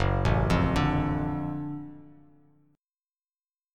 F#mM7#5 chord